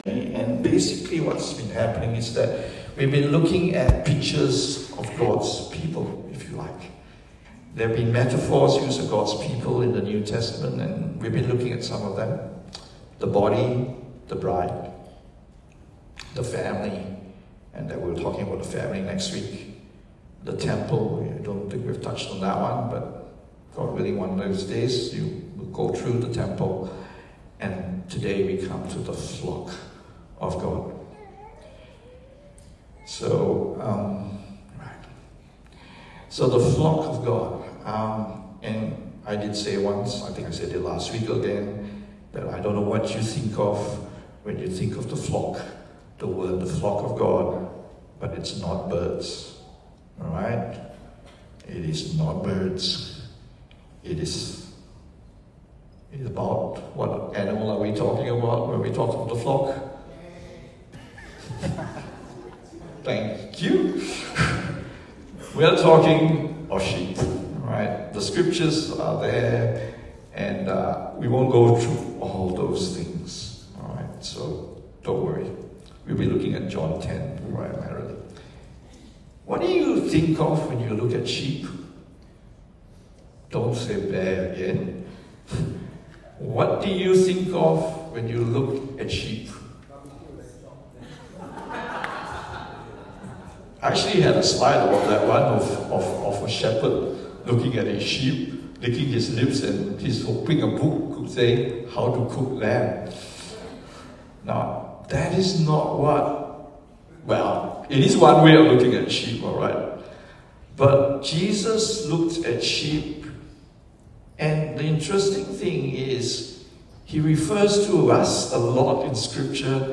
English Sermons | Casey Life International Church (CLIC)